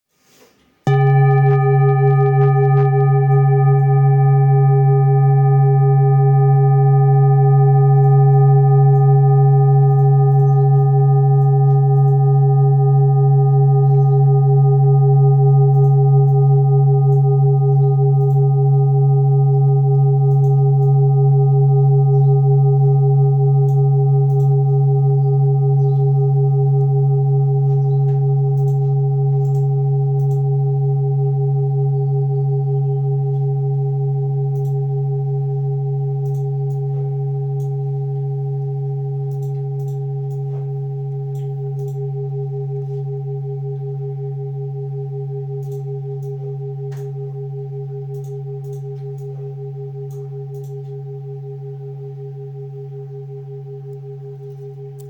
Jambati Singing Bowl-30402
Singing Bowl, Buddhist Hand Beaten, with Fine Etching Carving, Select Accessories
Material Seven Bronze Metal
Jamabati bowl is a hand-beaten bowl.
It can discharge an exceptionally low dependable tone.